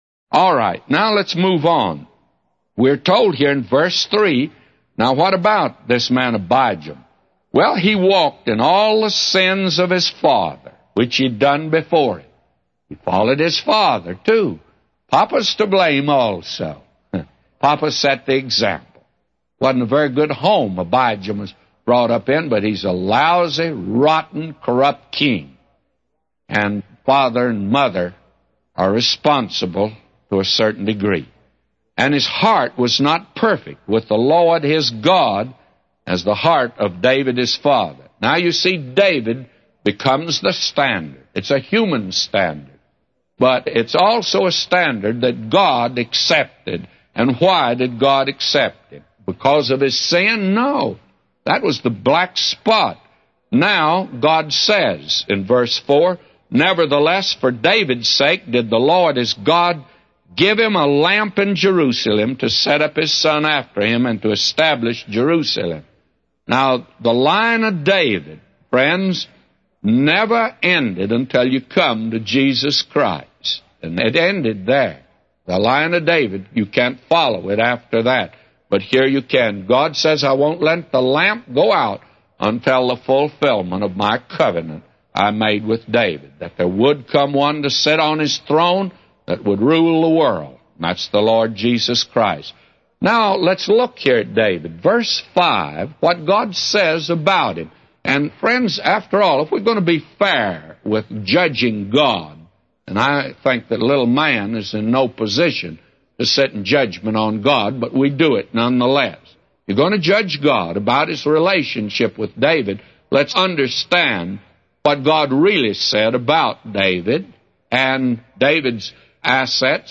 A Commentary By J Vernon MCgee For 1 Kings 15:3-999